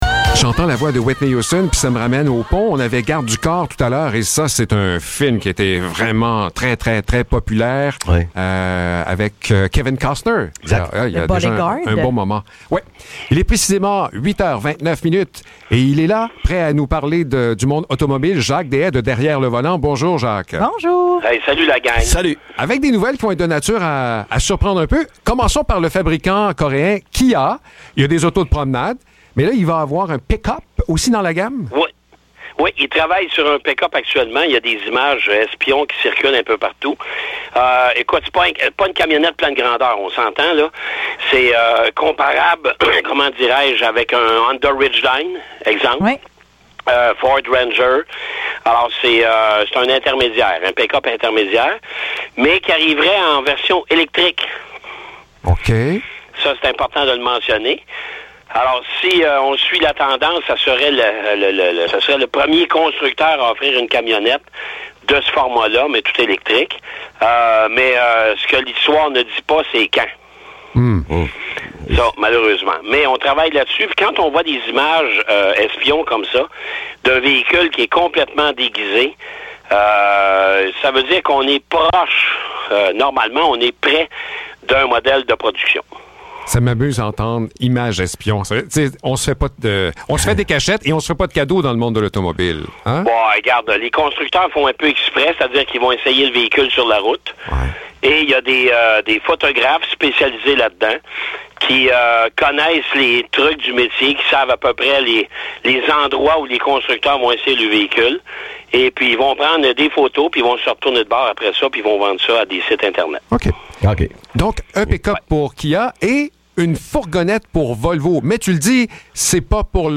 Chronique automobile